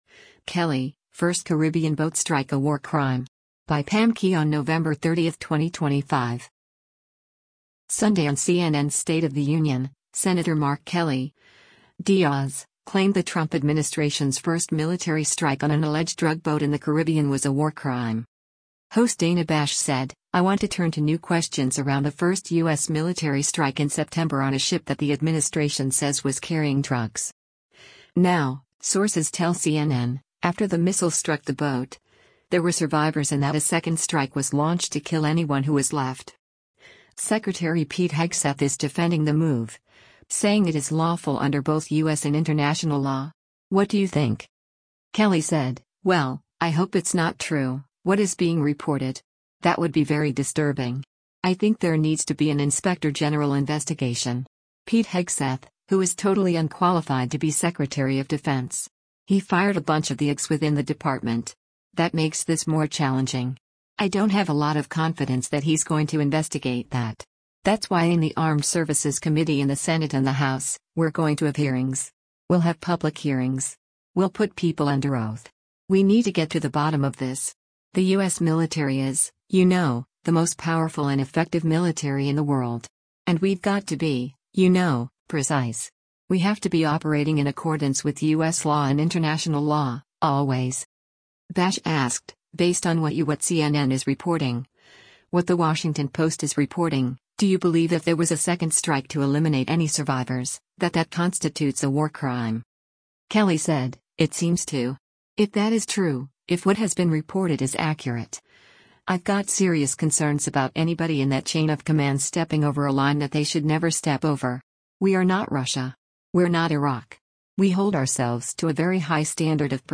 Sunday on CNN’s “State of the Union,” Sen. Mark Kelly (D-AZ) claimed the Trump administration’s first military strike on an alleged drug boat in the Caribbean was a “war crime.”